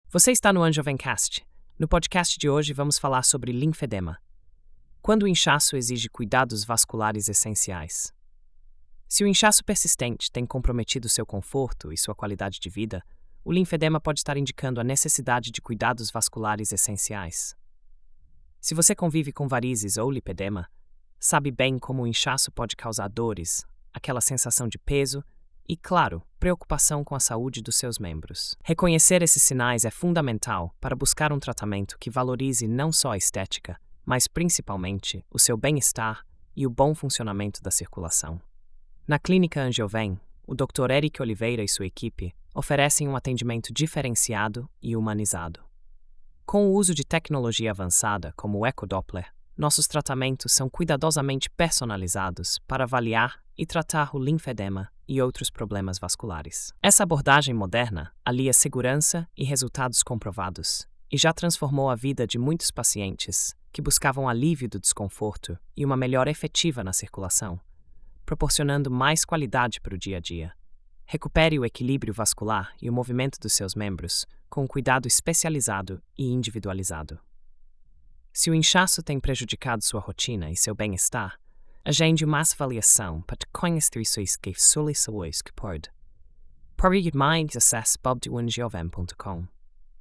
Narração automática por IA